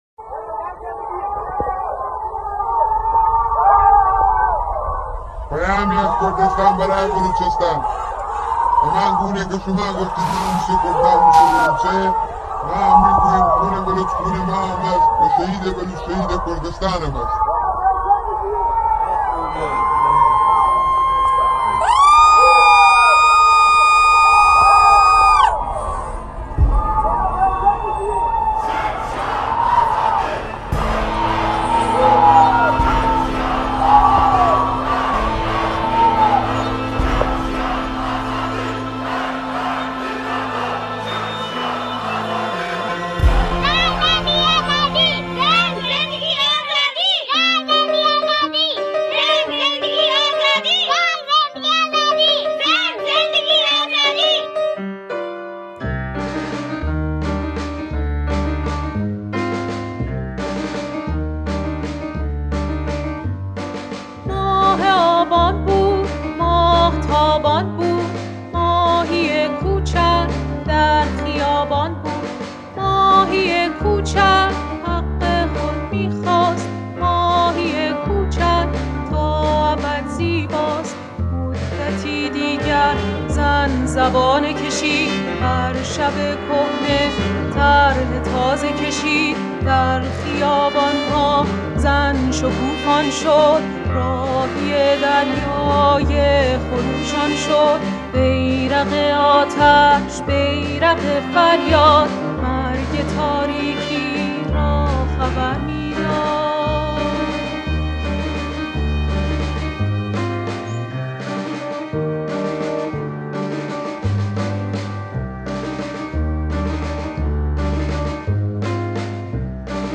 Sounds from the revolution and from the hearts of the streets are flowing through minds, devices, and continents, getting merged with new released revolutionary multilingual songs showing the strength and diversity of creative, powerful voices in resistance against the oppression.